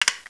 2CLICK.WAV